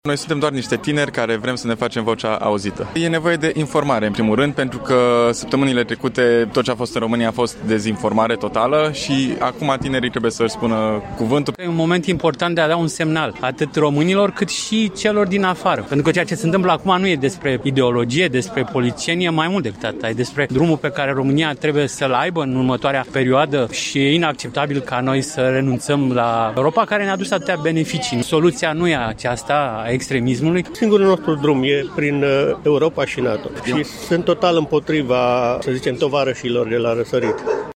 Sute de tineri au participat, în această seară, în centrul Timisoarei la o manifestare proeuropeană și antiextremism.
Manifestanții, în mare parte tineri, au declarat că acțiunea nu are substrat poltic, ci este doar de susținere a democrației și, în același timp, un semnal de alarmă.
vox-protest.mp3